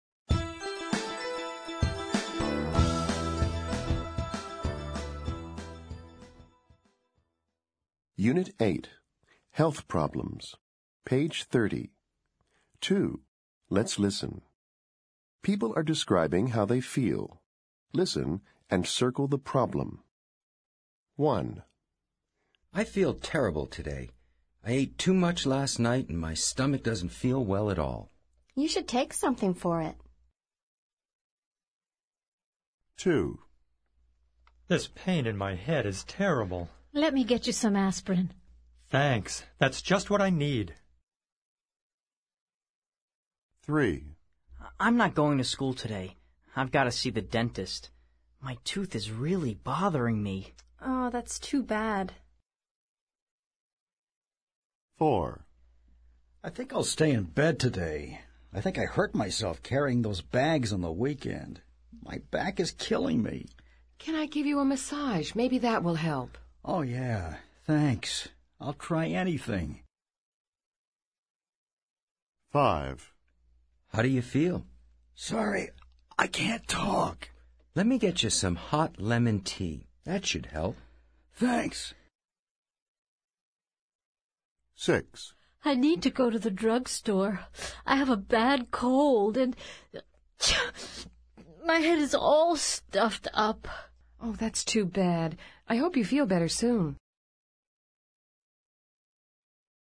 People are describing how they feel.